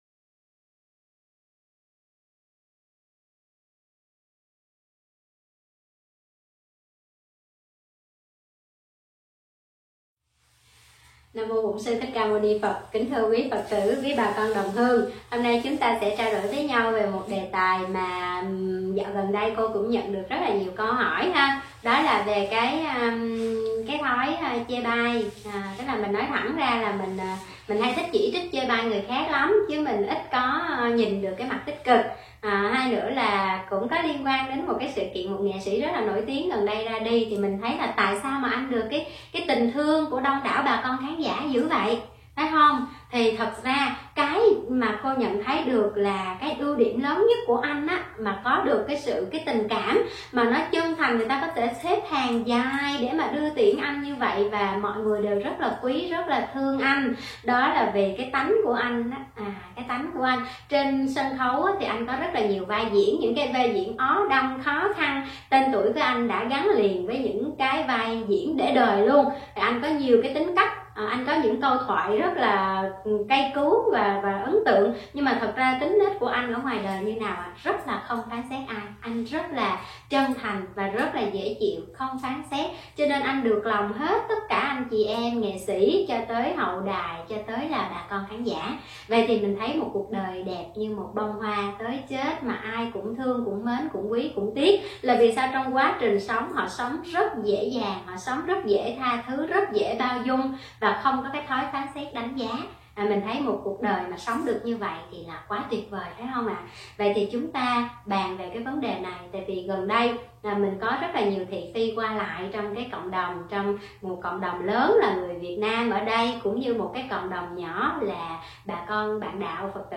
Mời quý phật tử nghe mp3 thuyết pháp Thay đổi thói quen chỉ trích - chê bai